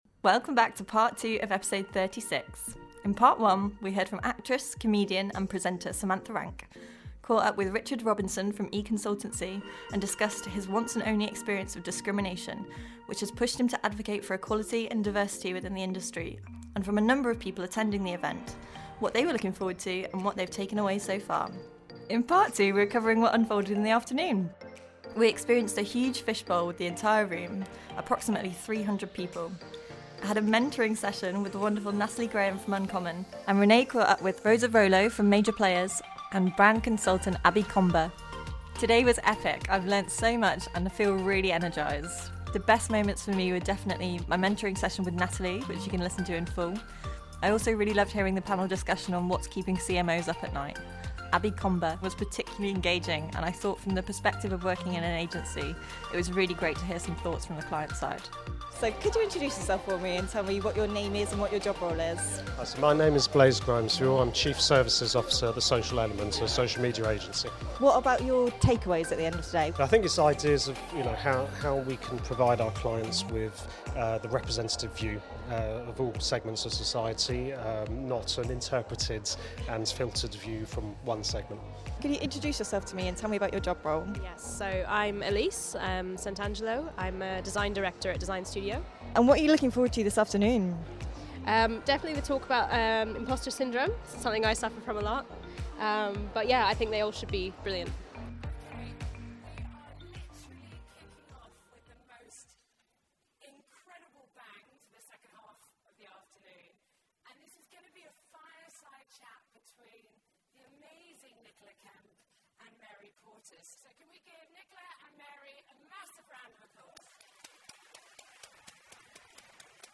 For part 2 of episode 36 we continue with a number of fantastic interviews from Creative Equals: Rise, that took place on the 15th May (a week ago).